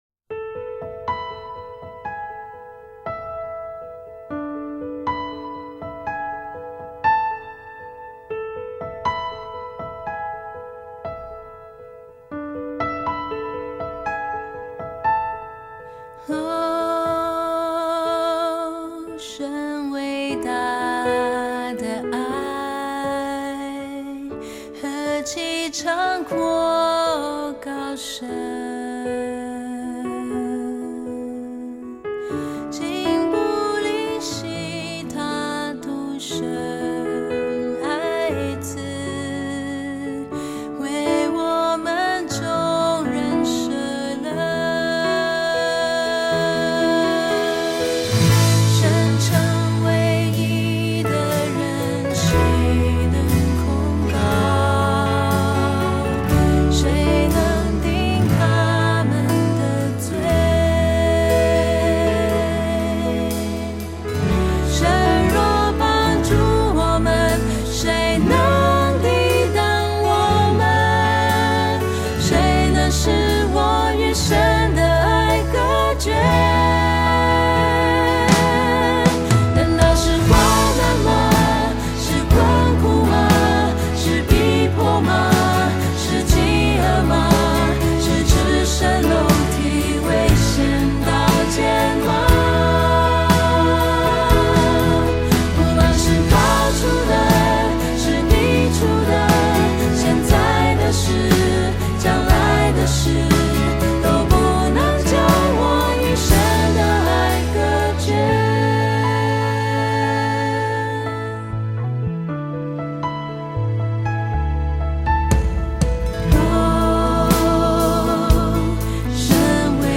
赞美诗